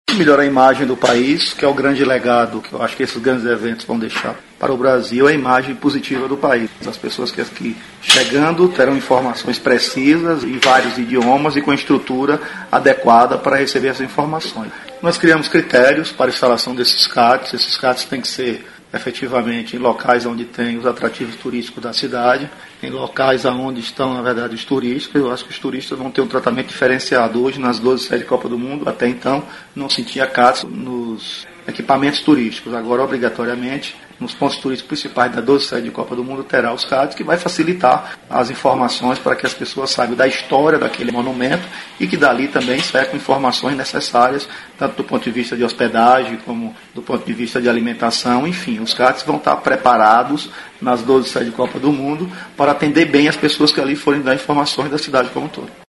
aqui para ouvir declaração do secretário Fábio Mota sobre a importância dos CATs para a recepção de visitantes.